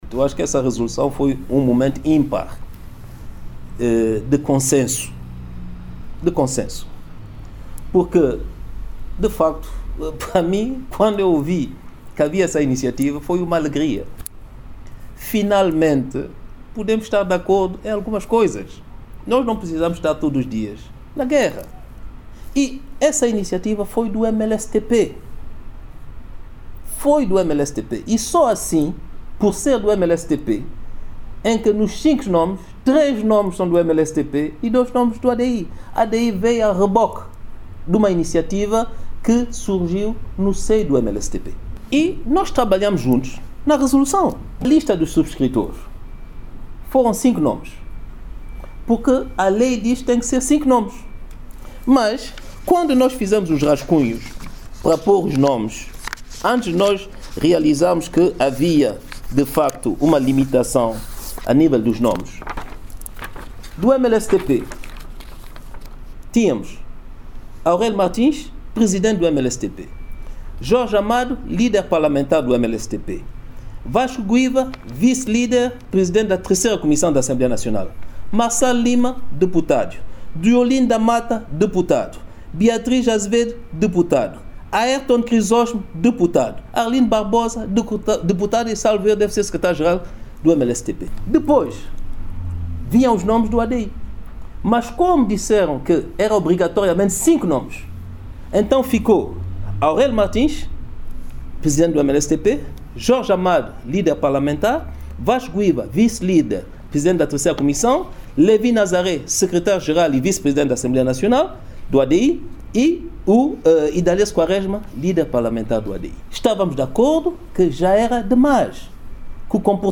Trovoada, que é também líder do ADI no poder, fez estas declarações numa entrevista a TVS e a Rádio Nacional, na qual falou dos últimos acontecimentos no âmbito do processo de resolução parlamentar de exoneração de três juízes do Supremo Tribunal que acabaram por ser reconduzidos em funções.
Declarações do Primeiro-Ministro Patrice Trovoada